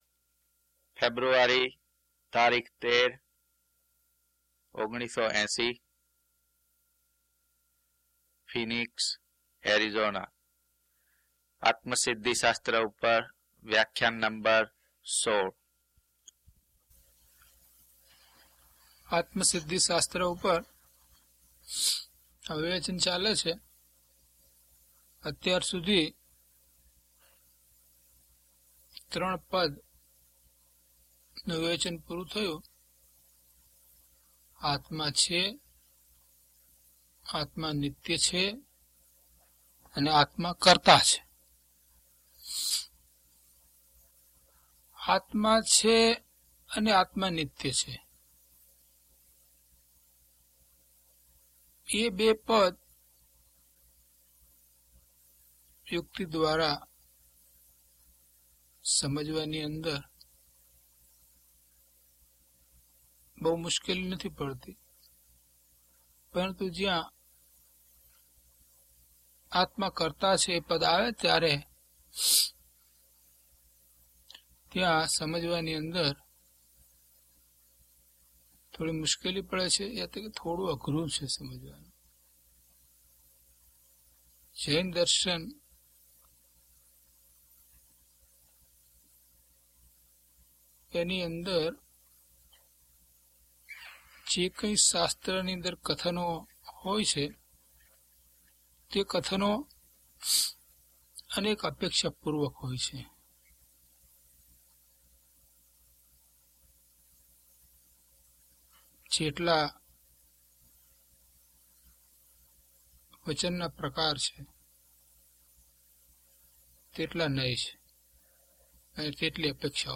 DHP025 Atmasiddhi Vivechan 16 - Pravachan.mp3